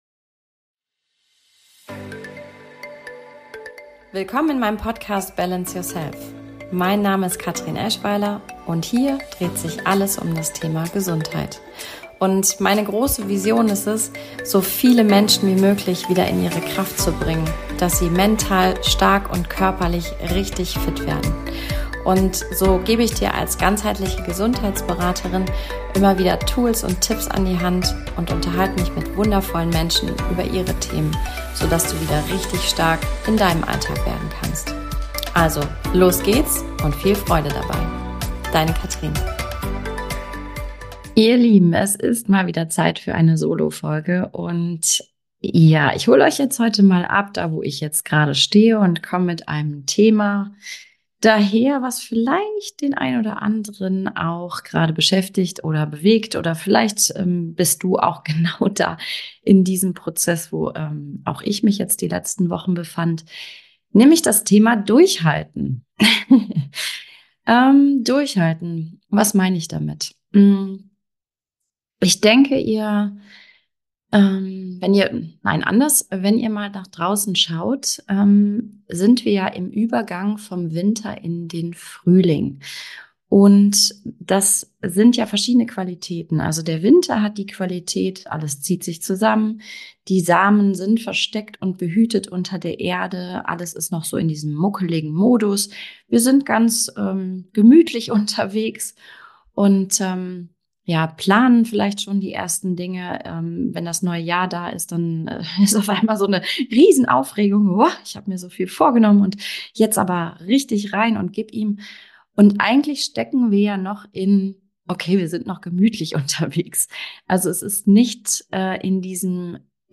#20 - Halte durch und vertraue - Solofolge